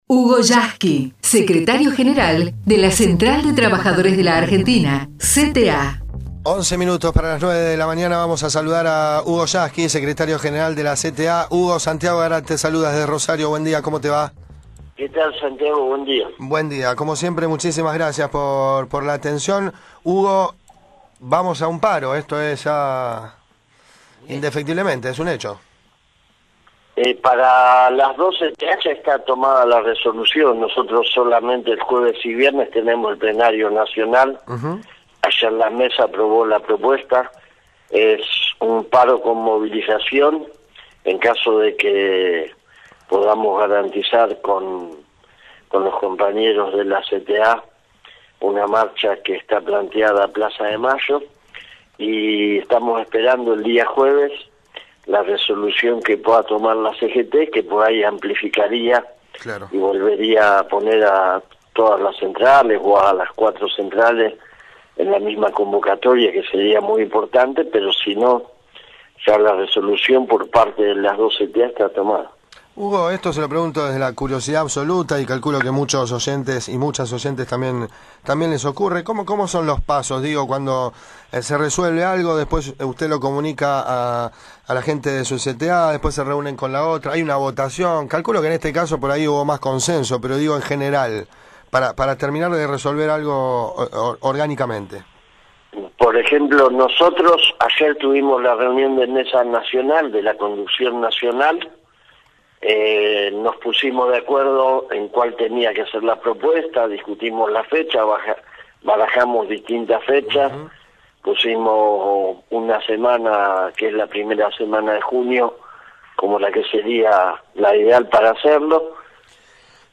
HUGO YASKY (entrevista) RADIO UNIVERSIDAD // ROSARIO